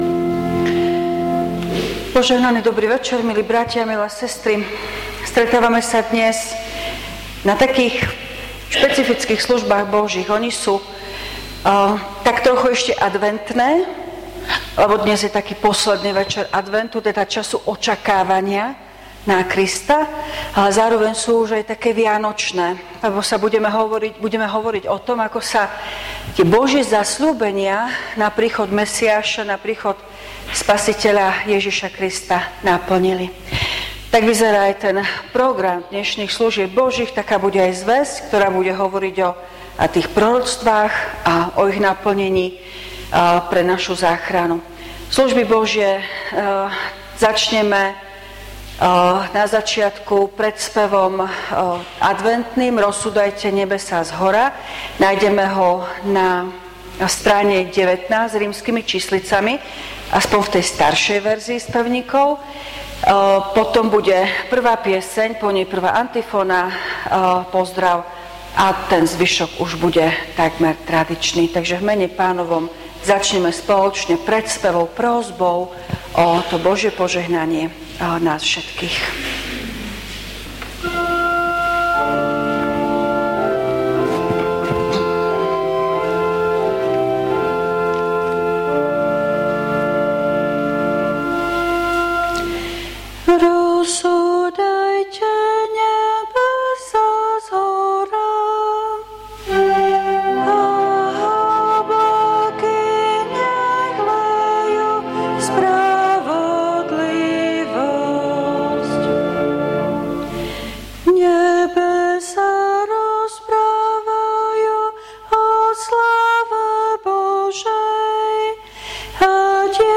Služby Božie – Štedrý večer
V nasledovnom článku si môžete vypočuť zvukový záznam zo služieb Božích – Štedrý večer.